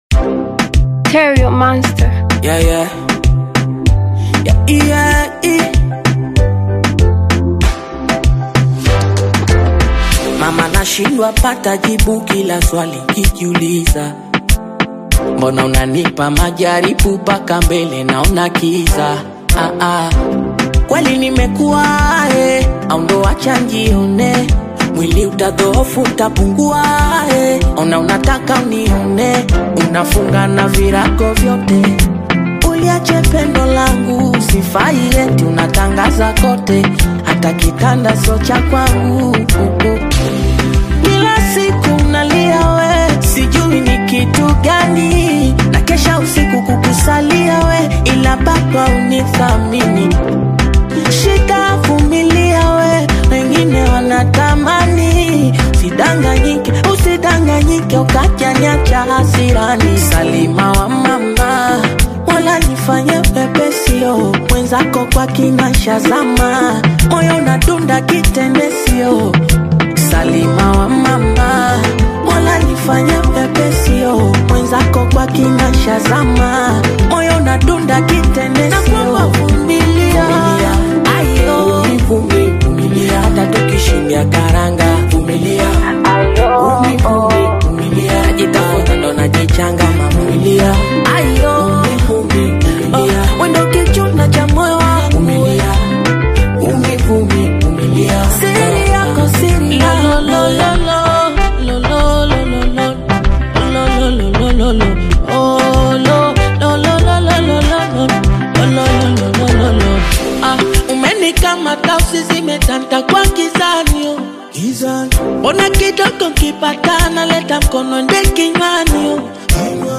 beautiful jam